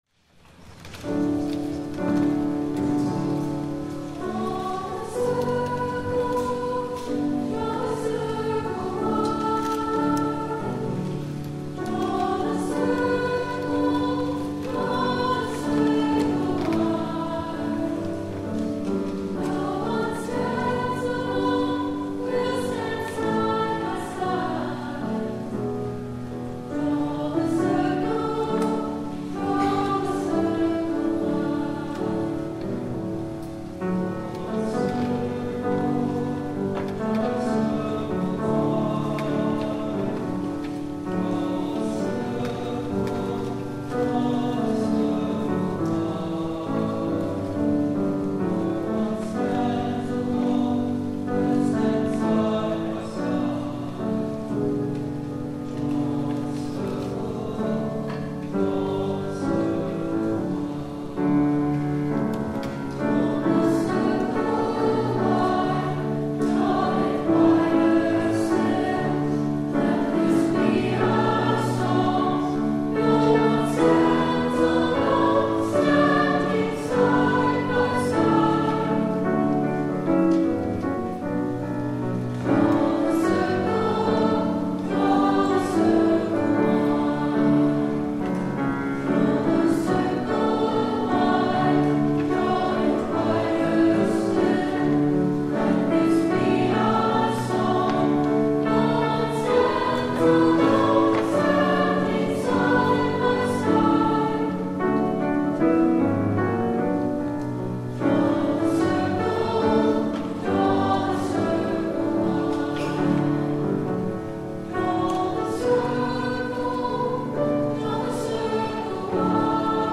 THE OFFERTORY
The Youth Choir